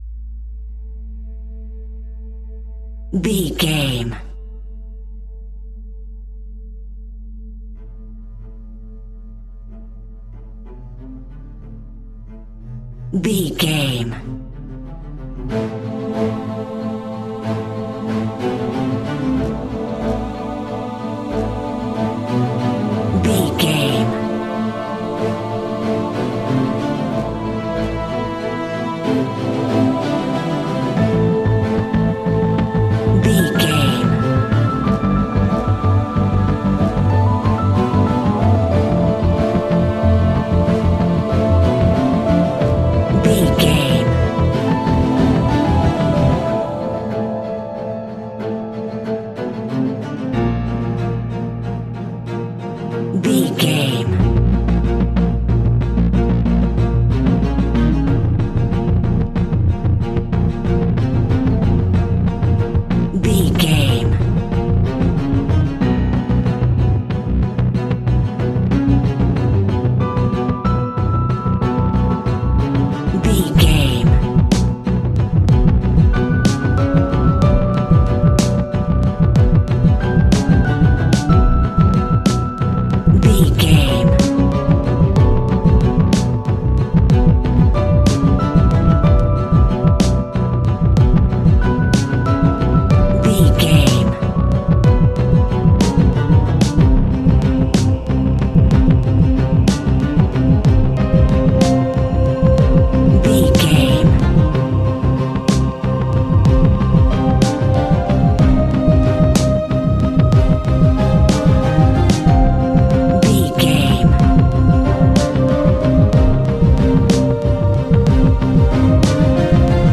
Fast paced
In-crescendo
Aeolian/Minor
strings
brass
percussion
synthesiser